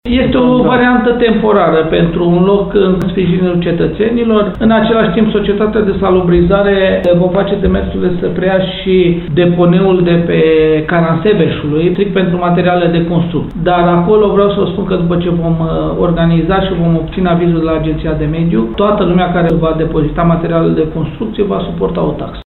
Primarul Călin Dobra spune că, acum, se caută un loc pentru depozitarea acestor deșeuri nepoluante.